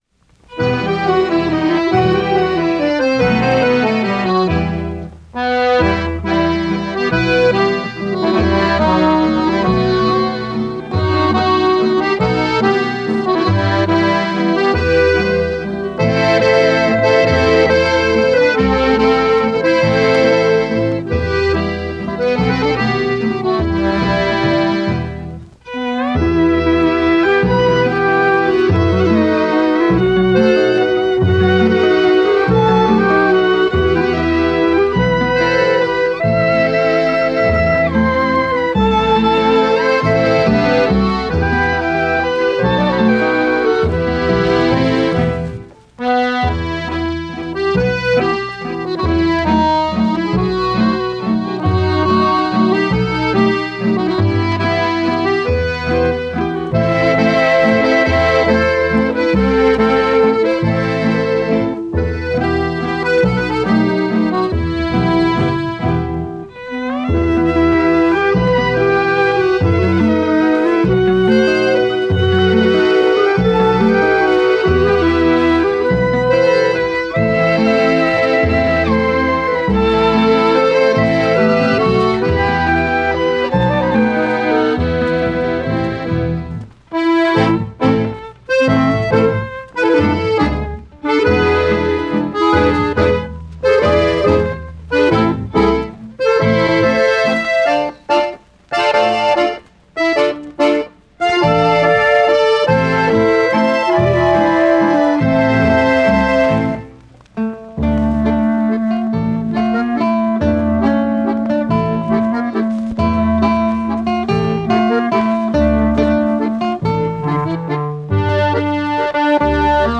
Couple Dance Instrumental
Каталожная категория: Инструментальная музыка |
Жанр: Бальный танец
Исполнитель: Народный оркестр Михаила Германа
Место записи: Нью-Йорк